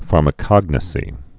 (färmə-kŏgnə-sē)